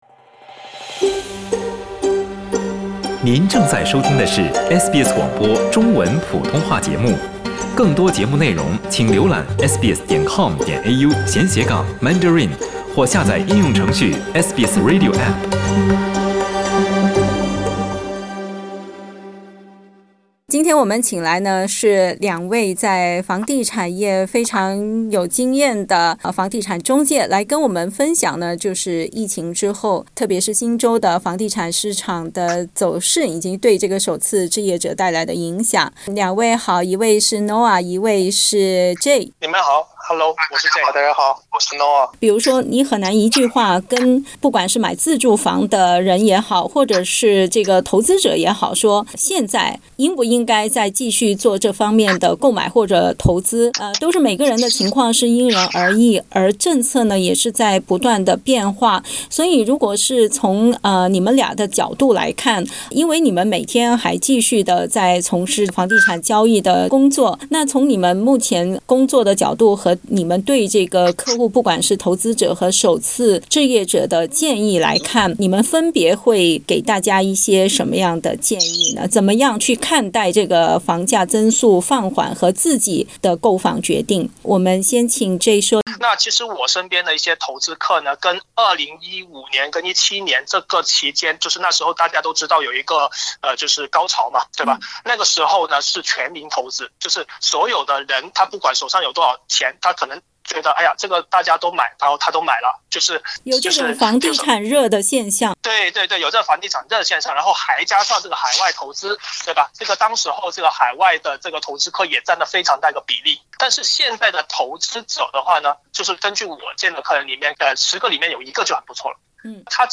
（点击图片收听采访，本节目为嘉宾观点，不代表本台立场） 澳大利亚人必须与他人保持至少1.5米的社交距离，请查看您所在州或领地的最新社交限制措施。